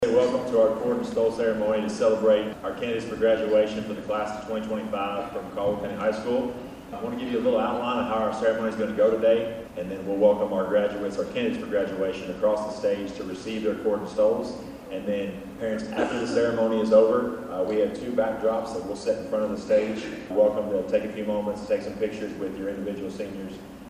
The senior class of Caldwell County High School received their graduation cords and stoles Monday morning in a special presentation in the high school Fine Arts Building.